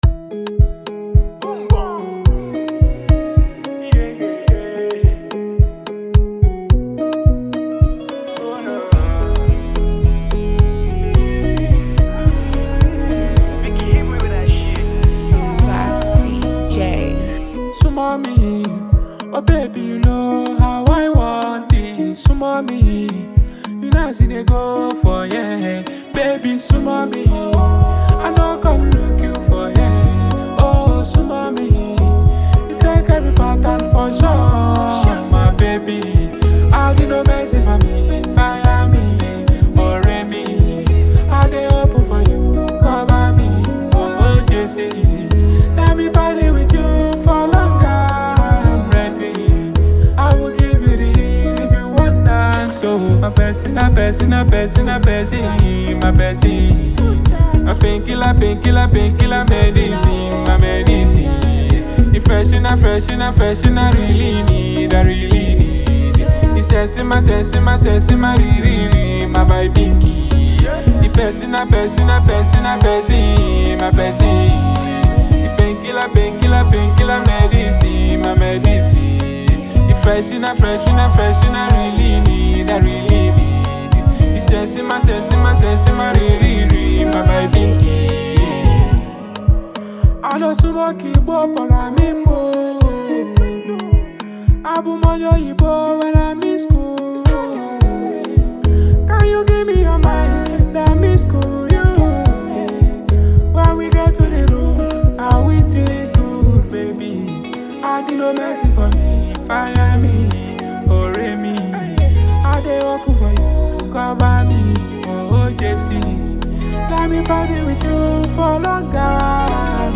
soulful Afro-fusion track
With smooth rhythms and heartfelt lyrics
both romantic and celebratory